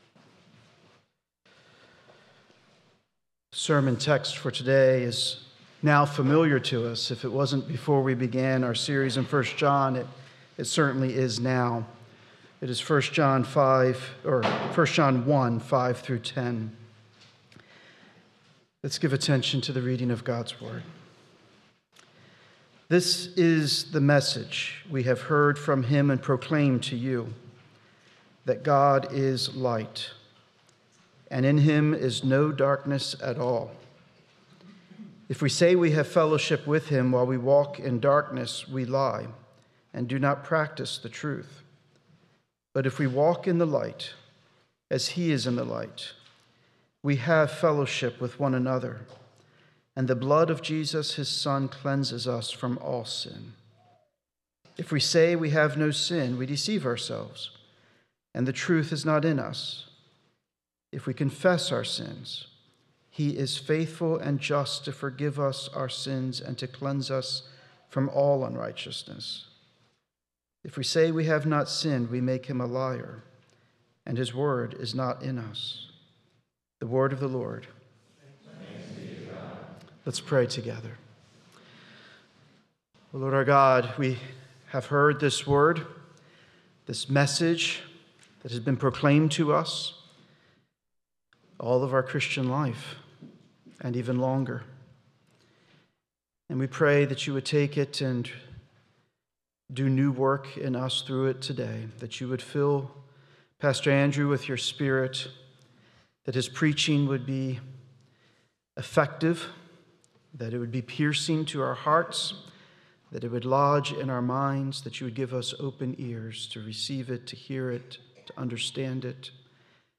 11.9.25 sermon.m4a